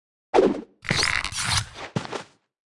Media:Sfx_Anim_Ultra_Bo.wav 动作音效 anim 在广场点击初级、经典、高手和顶尖形态或者查看其技能时触发动作的音效
Sfx_Anim_Super_Bo.wav